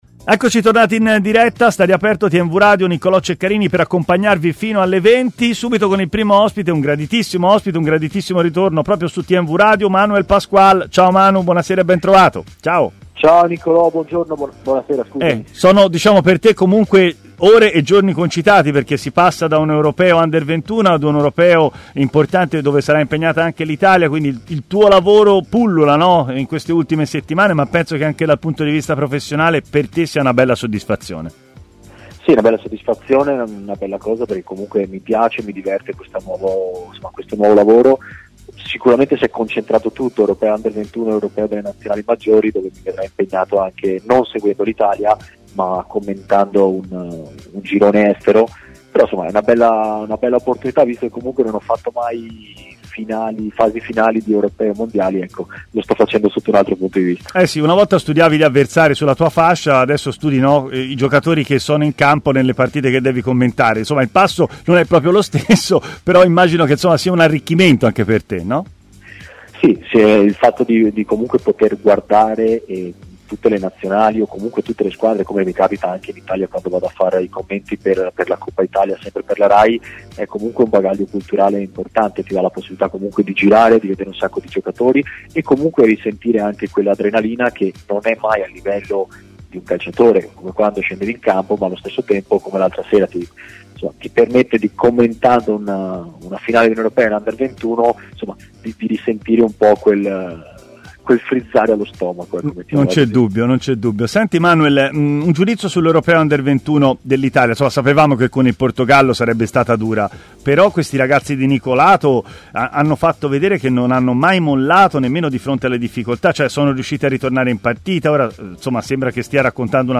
Manuel Pasqual, ex difensore e commentatore tv per Rai Sport, ha parlato in diretta a TMW Radio, nel corso della trasmissione Stadio Aperto